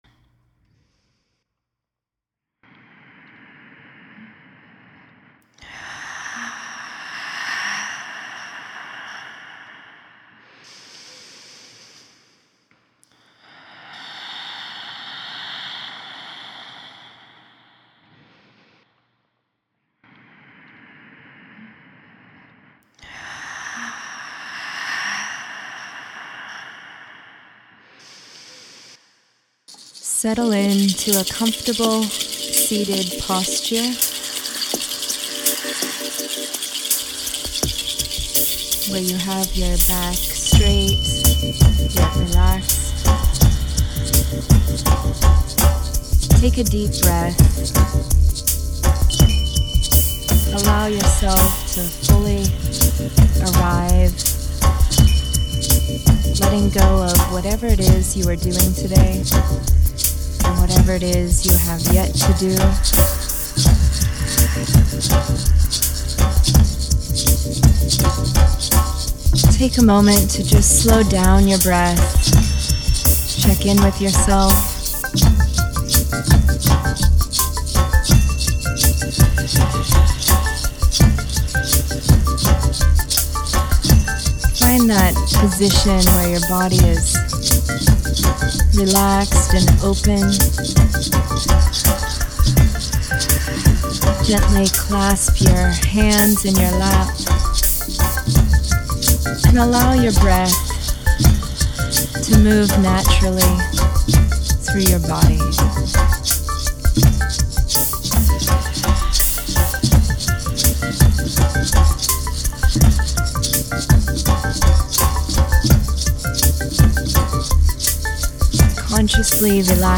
11 MIN MEDITATION FOR DAILY ALIGNMENT